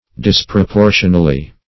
Search Result for " disproportionally" : The Collaborative International Dictionary of English v.0.48: Disproportionally \Dis`pro*por"tion*al*ly\, adv. In a disproportional manner; unsuitably in form, quantity, or value; unequally.